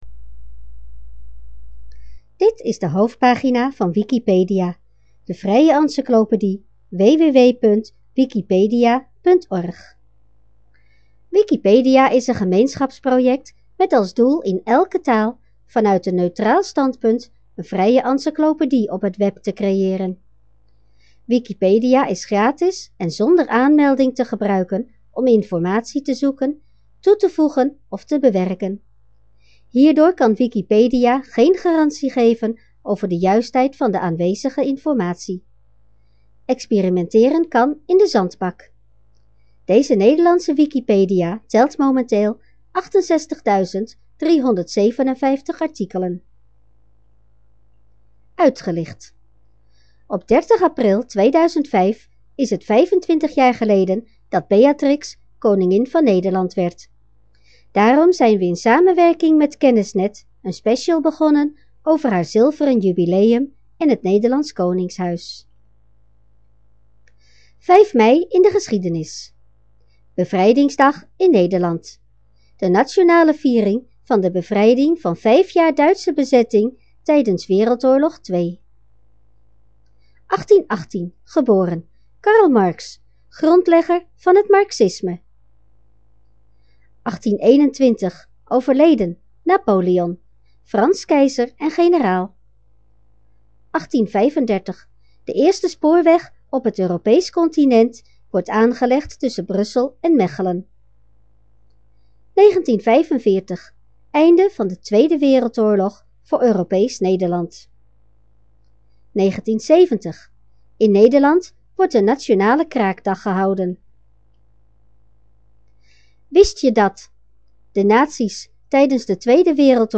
Beschrijving Oude ingesproken versies van de hoofdpagina - NIET VERWIJDEREN Let op: dit bestand dient regelmatig, liefst dagelijks, opnieuw te worden ingesproken. Bron Eigen werk; Zelf ingesproken.